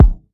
shortbreak_kick.wav